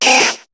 Cri de Rattata dans Pokémon HOME.